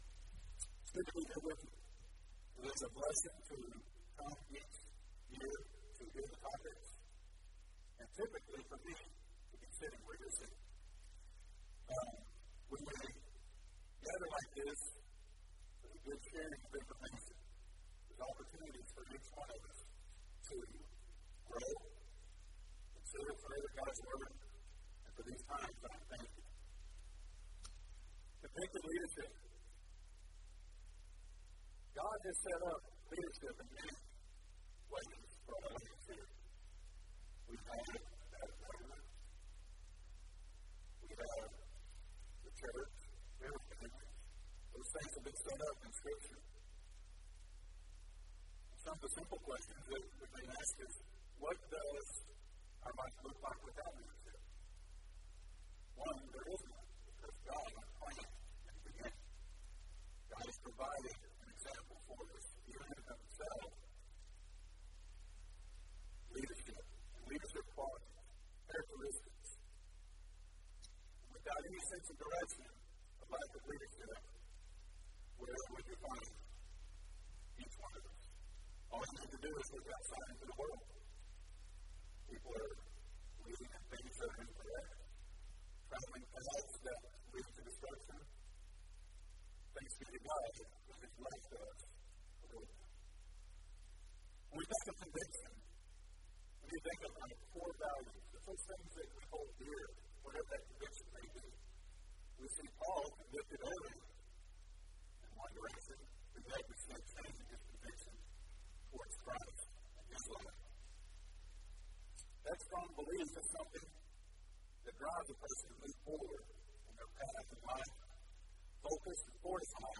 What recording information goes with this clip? Alternate File Link File Details: Series: Arise: Southwest Spiritual Growth Workshop Event: 7th Annual Arise: Southwest Spiritual Growth Workshop Theme/Title: Arise with Conviction!